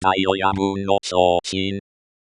phonemes dä.i o̞.jä.bɯ̟ᵝn no̞ tjo̞ː.tin
pronunciation o̞.jä.bɯ̟̃ᵝn t̠͡ɕo̞ː.t̠͡ɕĩŋ